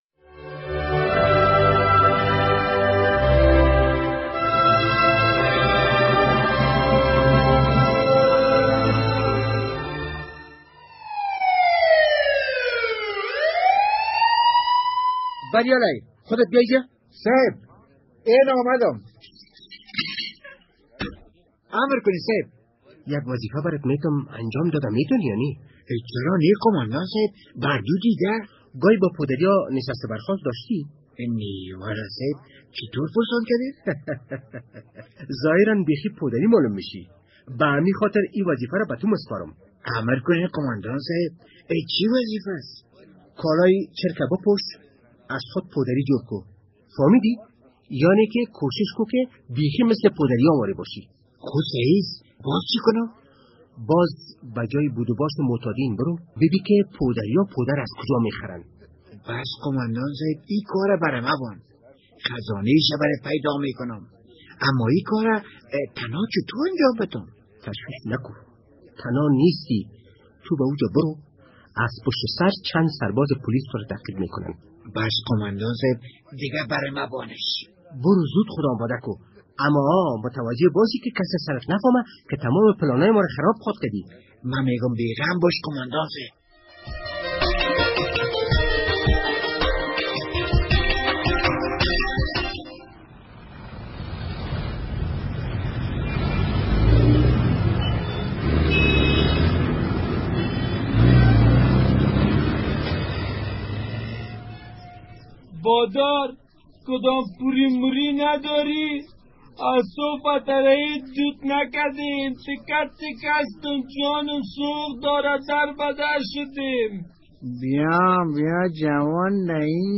درامه کاروان زهر